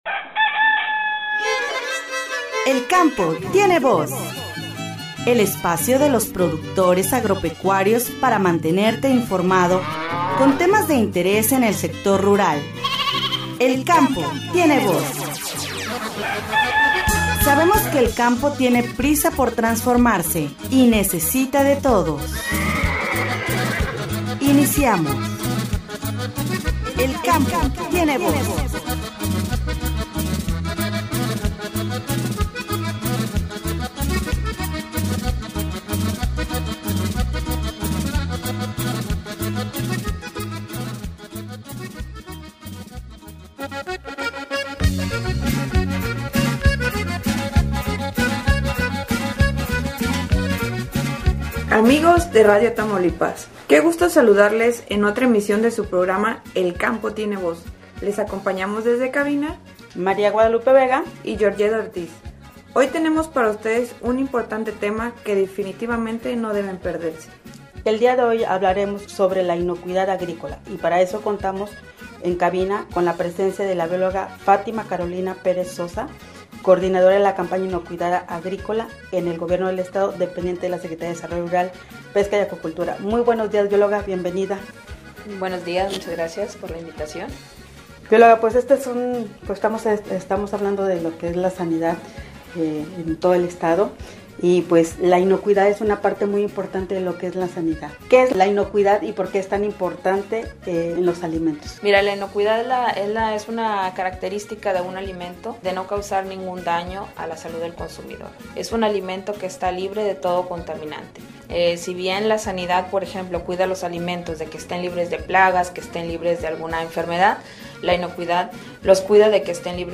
“El Campo Tiene Voz”, en esta entrevista platicamos con la Biol.